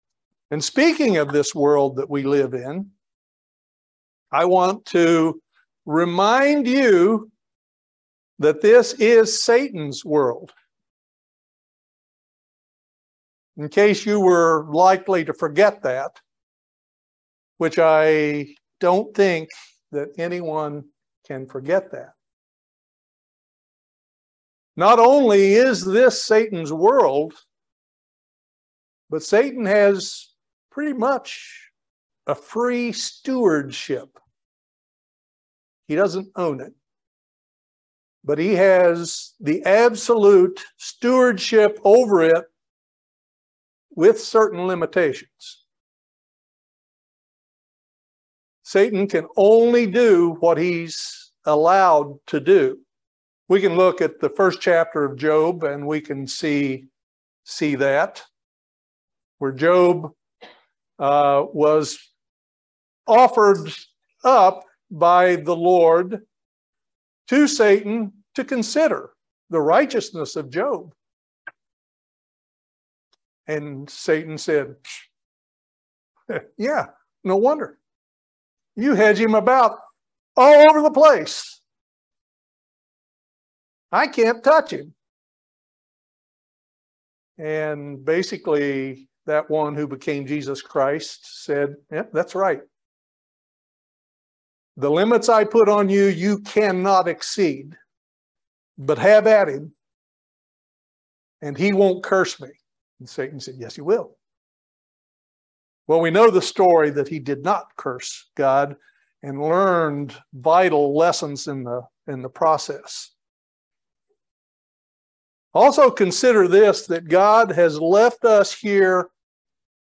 This Sermon discusses how the pathway to the Kingdom for all Christians is strewn with many obstacles and oppositions. It requires us to endure and overcome in order to pass through that narrow gate, but the reward is worth any sacrifice.
Given in Lexington, KY